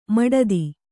♪ maḍadi